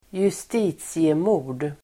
Uttal: [²just'i:tsiemo:r_d]